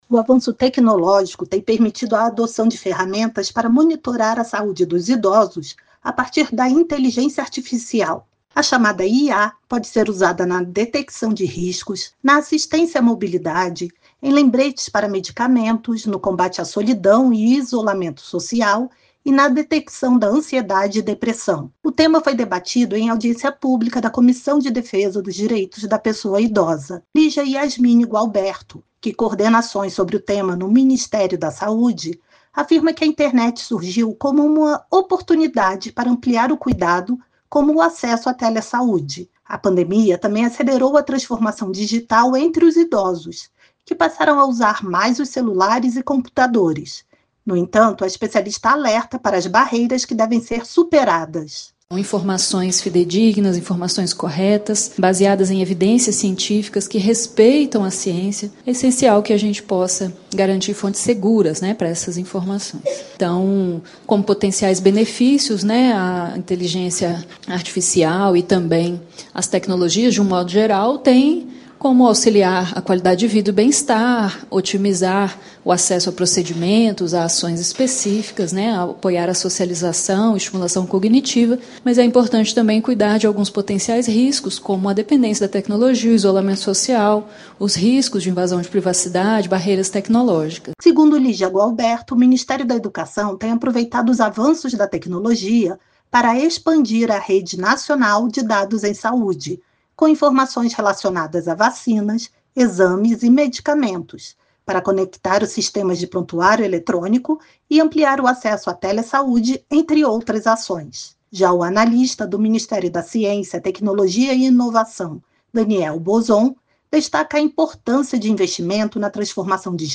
INTELIGÊNCIA ARTIFICIAL PODE AJUDAR IDOSOS A CUIDAR DA SAÚDE. O POTENCIAL DA IA NO DIA A DIA DA POPULAÇÃO IDOSA FOI DEBATIDA EM AUDIÊNCIA PÚBLICA NA CÂMARA, COMO INFORMA A REPÓRTER